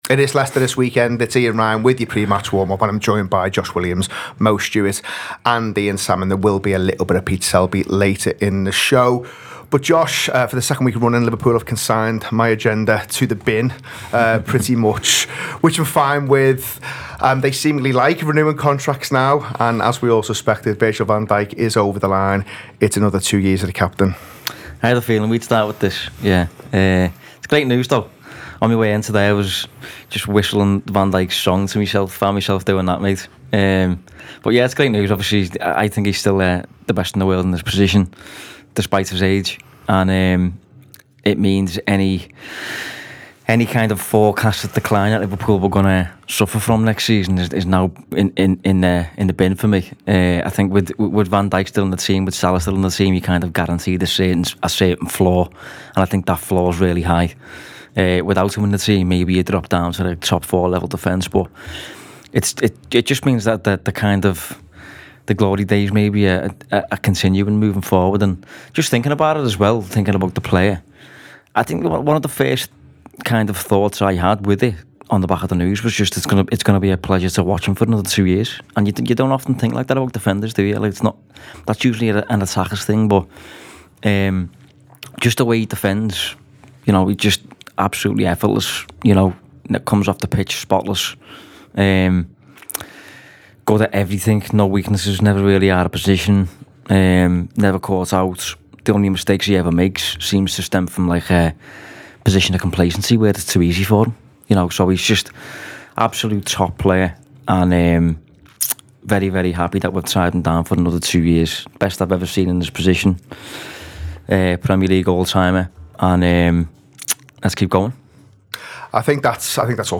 Below is a clip from the show – subscribe for more pre-match build up around Leicester City v Liverpool…